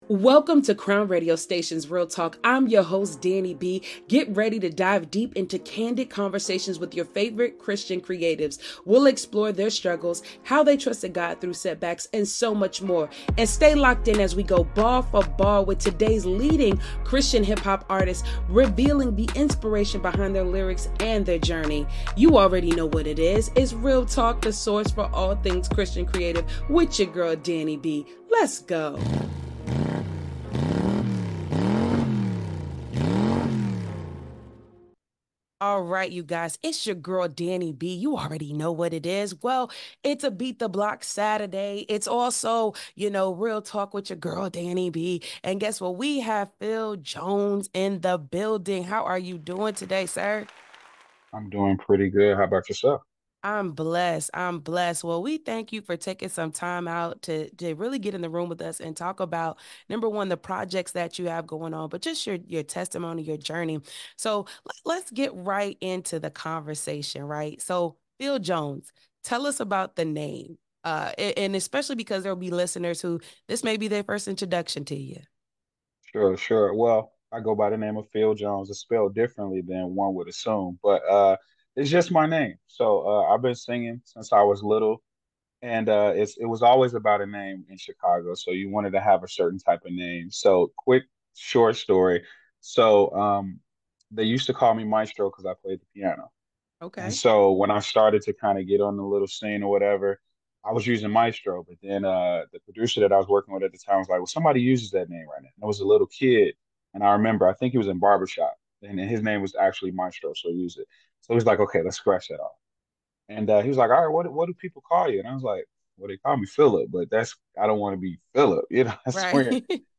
Exclusive Interview w